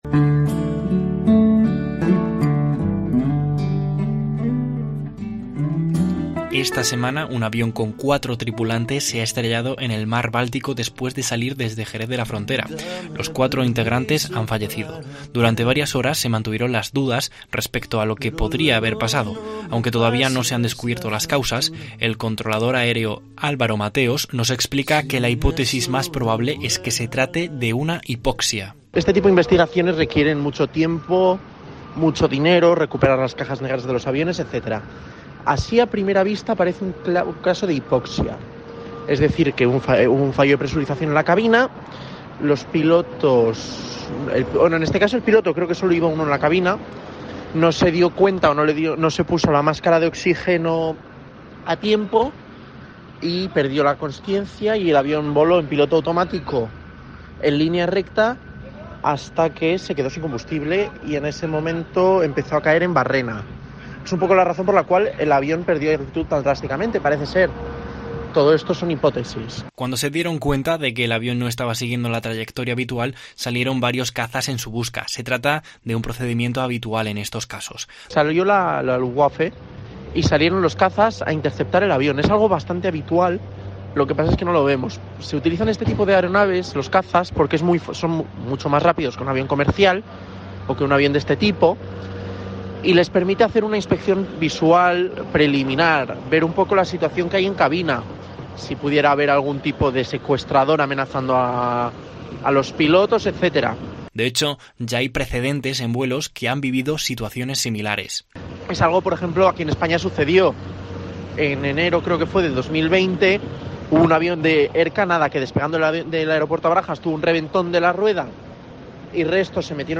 Un controlador aéreo desvela la posible causa del accidente aéreo del Báltico: "No se dieron cuenta"